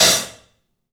Index of /90_sSampleCDs/E-MU Producer Series Vol. 5 – 3-D Audio Collection/3DPercussives/3DPAHat